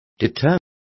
Also find out how disuadido is pronounced correctly.